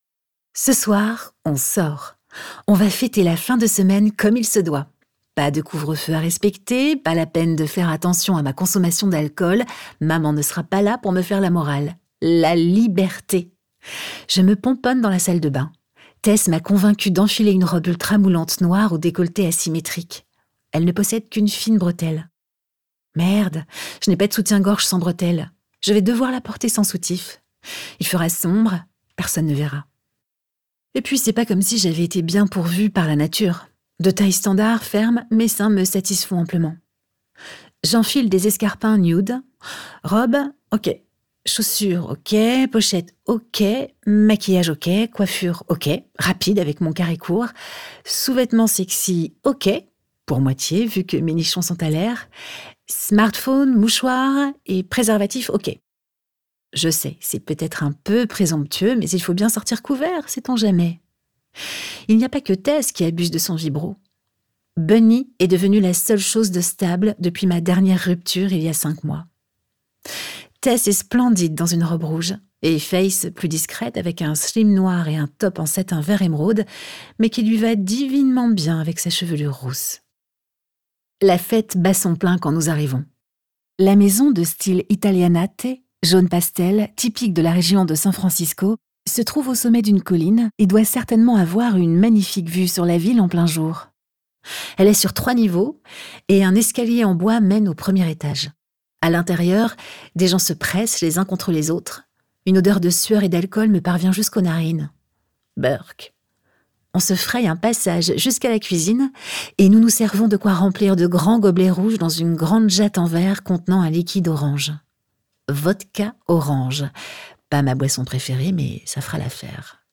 je découvre un extrait - Le voisin parfait est un enfoiré de Camilla Simon